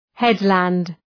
Προφορά
{‘hed,lænd}